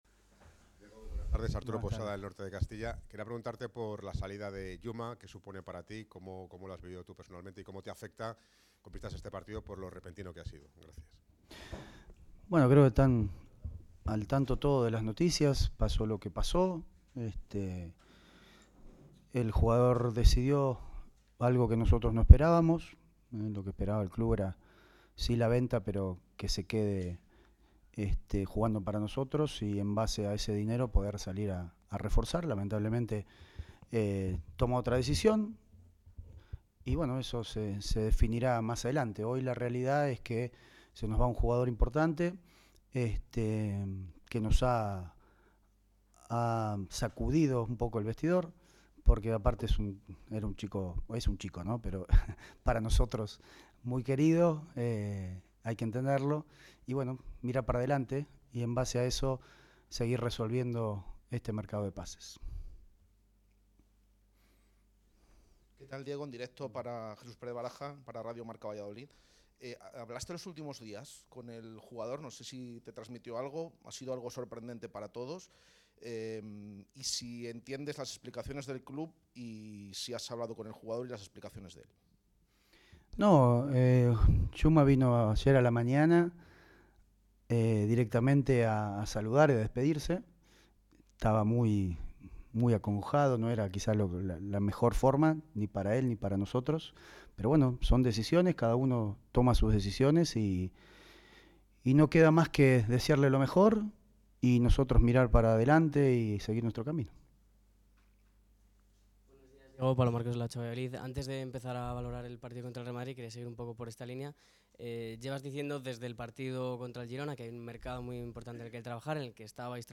Diego Cocca abordó en sala de prensa la situación de un grupo que quiere demostrar a la afición que está comprometido.